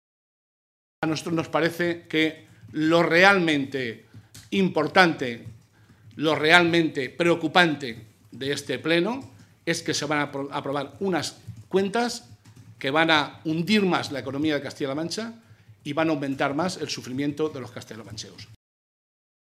Molina, que ofreció una rueda de prensa momentos antes del inicio del pleno que se desarrollará hoy y mañana en la cámara regional, indicó que lo más grave de las cuentas para el próximo año es que “son totalmente injustas, antisociales y vuelven a atacar a los más débiles”.